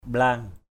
/ɓla:ŋ/